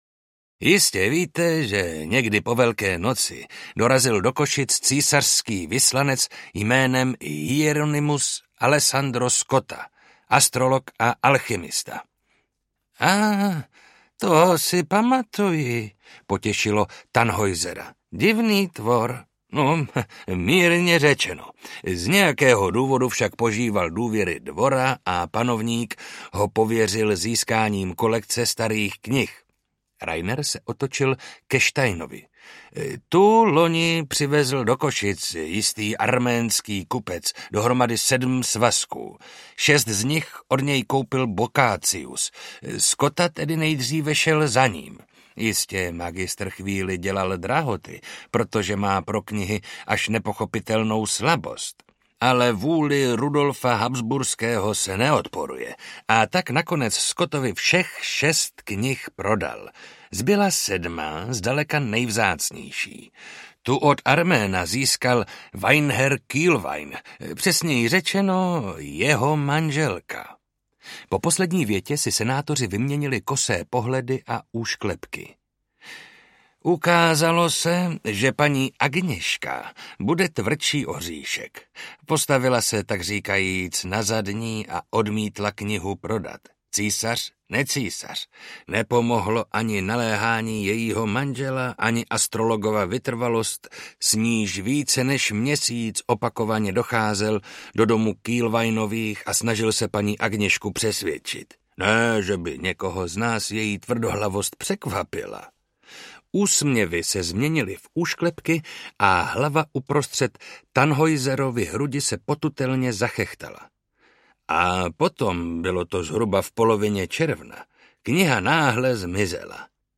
Prokletá kniha audiokniha
Ukázka z knihy